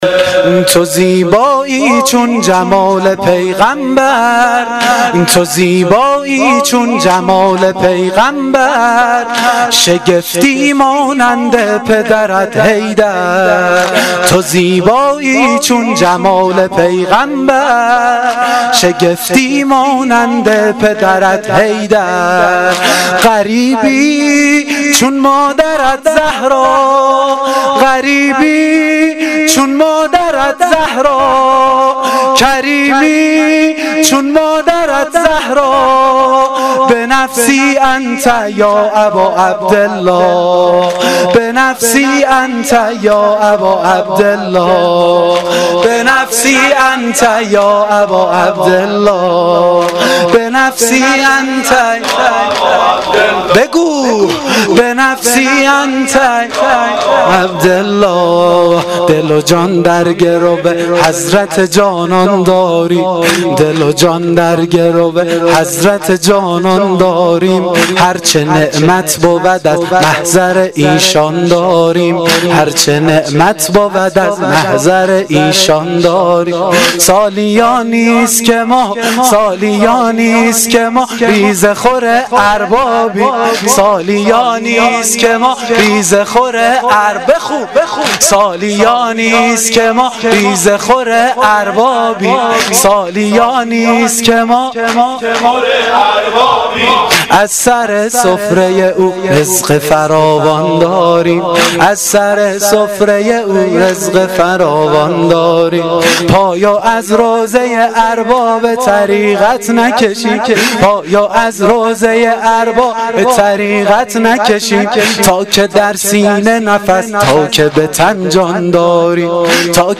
واحد شب دهم محرم الحرام 1396 (شب عاشورا)